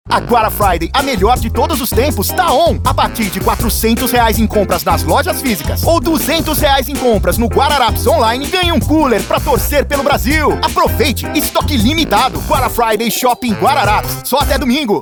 Tenho um home studio de nível profissional.
Jovem adulto